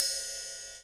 Drum Samples
R i d e s